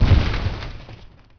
collapse.wav